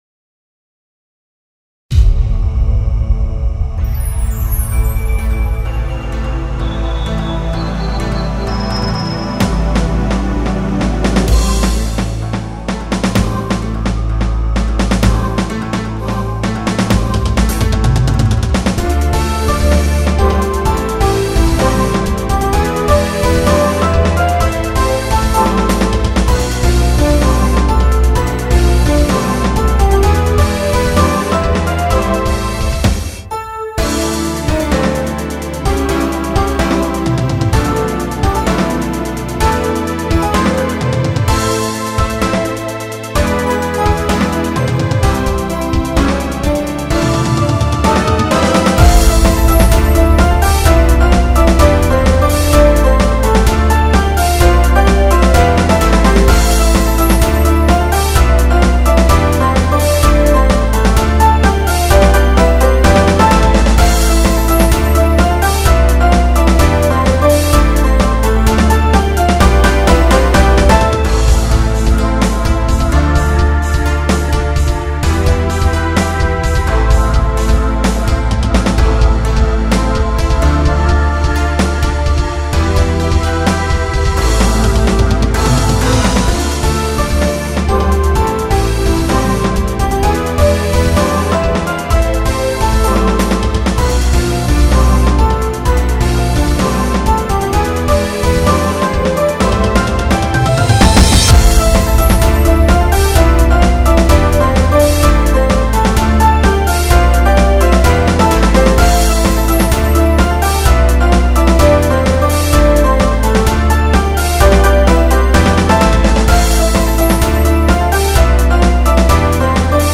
ポップ明るい激しい